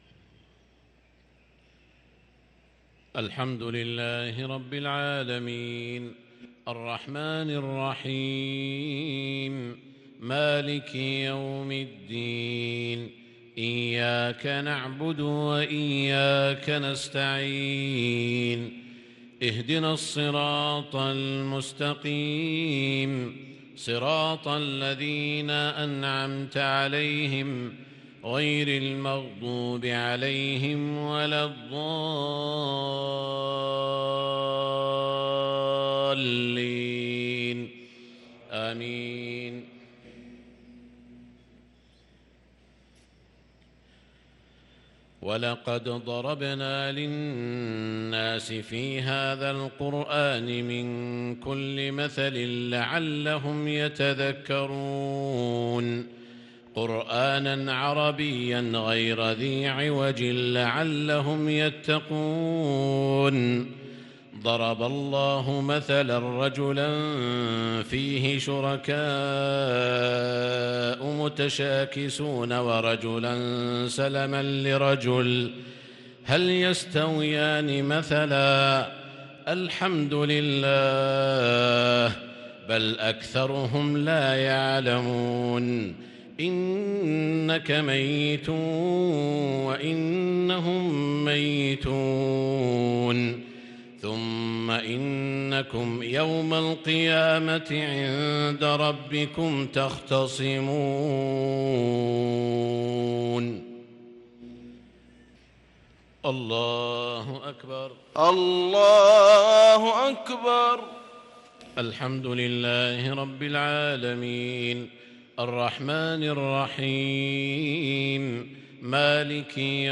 صلاة المغرب للقارئ سعود الشريم 7 ربيع الأول 1444 هـ
تِلَاوَات الْحَرَمَيْن .